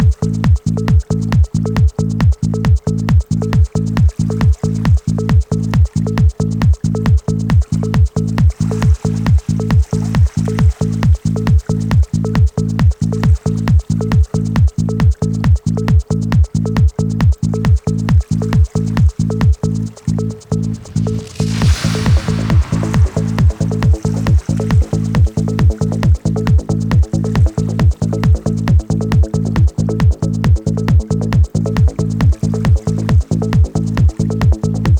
Жанр: Танцевальные / Электроника / Хаус / Транс